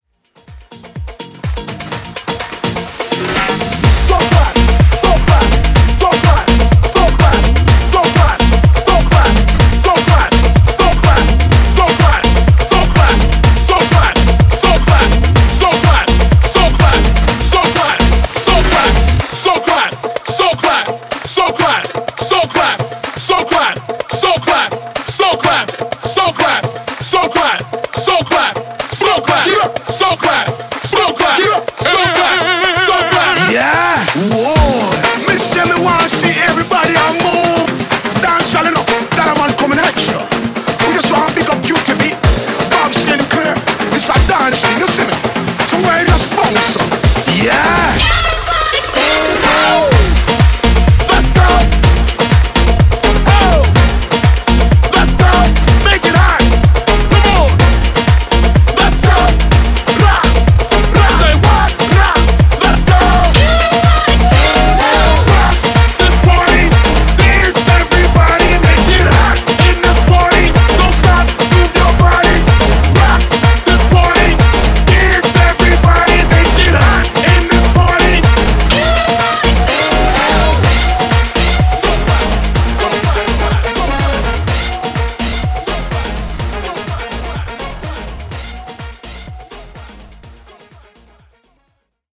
Afro House)Date Added